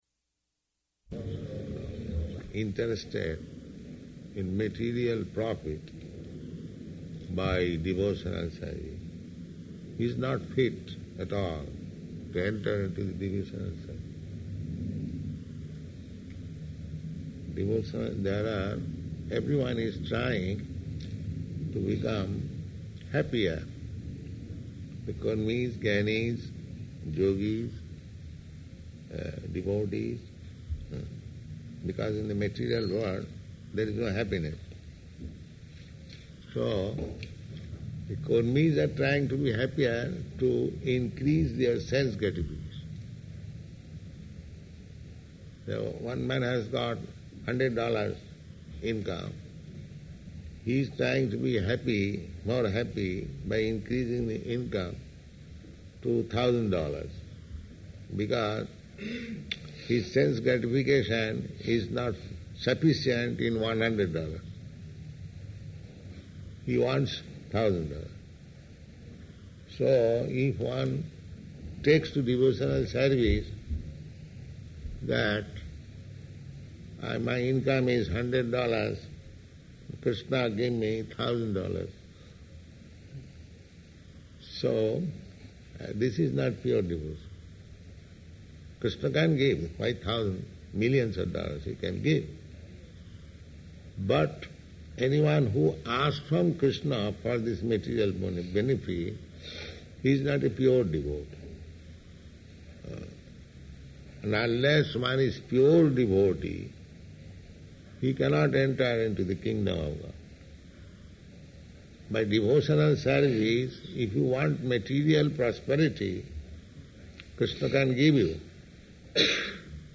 Initiation Lecture [partially recorded]
Location: Los Angeles